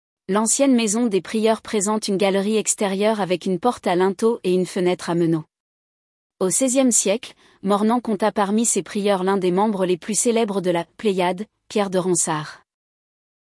audio guide de la maison des prieurs